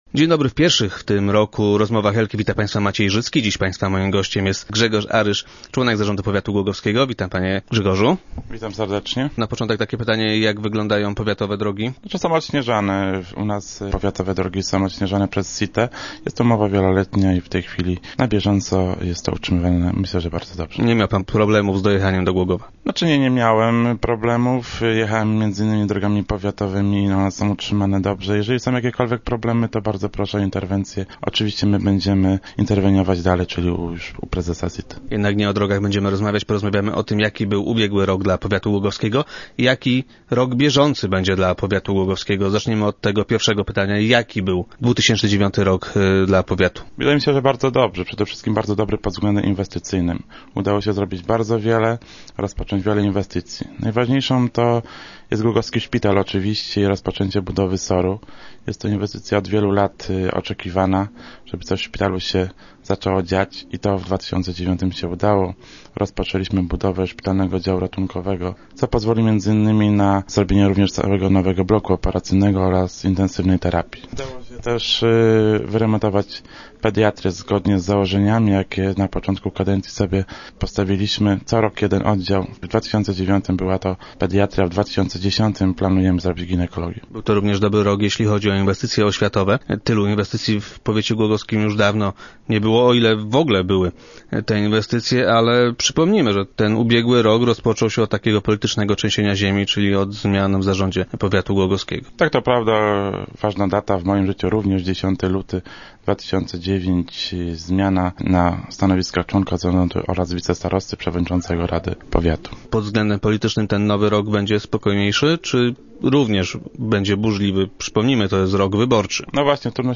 Jak zapewnił Grzegorz Aryż, członek zarządu powiatu, podobnie będzie w nowym roku.
Jak powiedział na radiowej antenie, nie można wykluczyć tego, że stanie w wyborcze szranki także w tym roku.